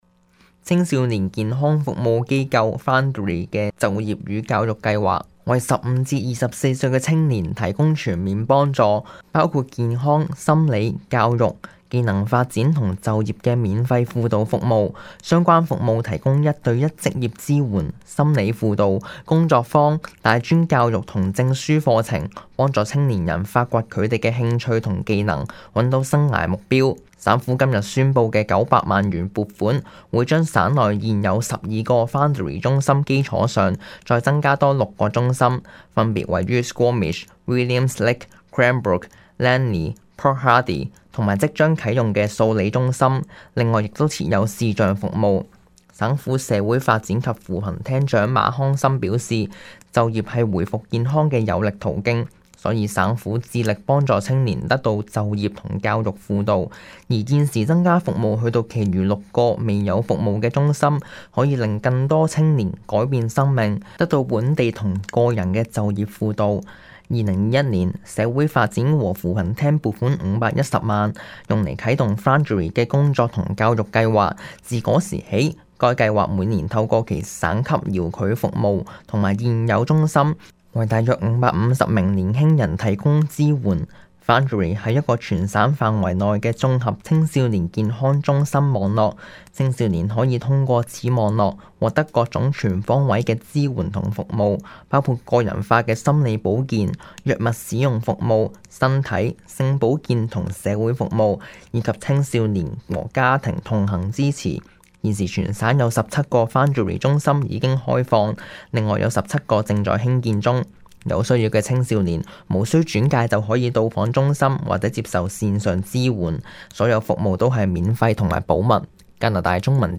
Local News 本地新聞